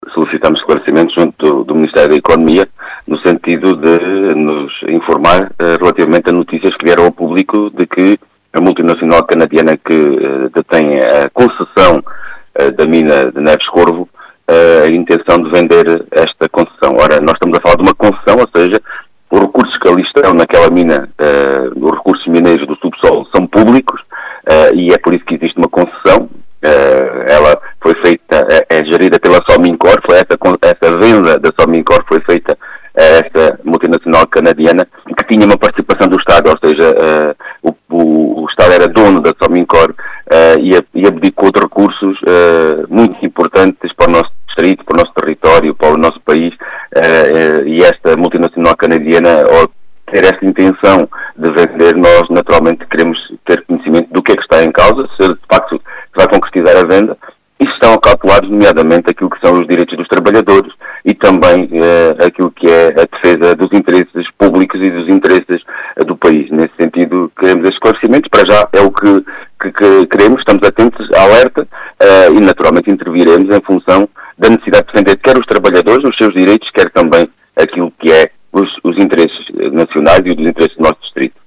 As explicações são de João Dias, deputado do PCP eleito por Beja.